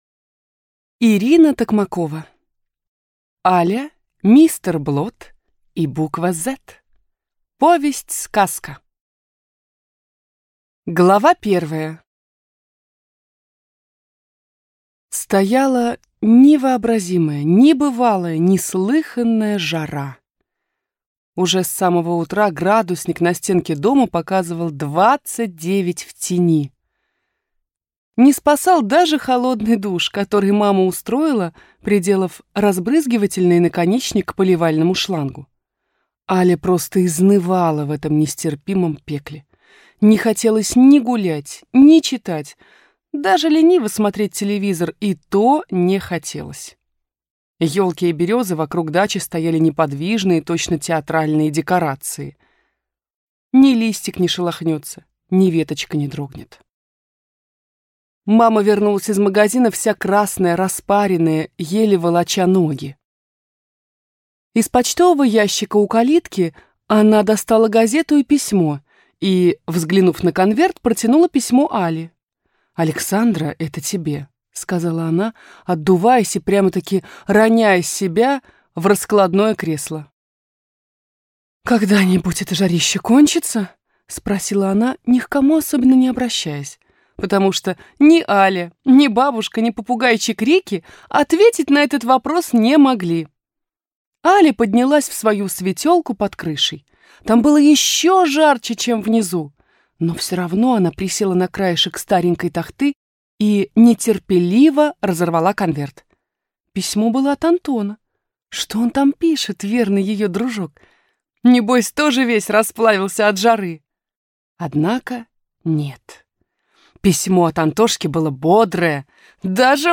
Аудиокнига Аля, мистер Блот и буква «Z» | Библиотека аудиокниг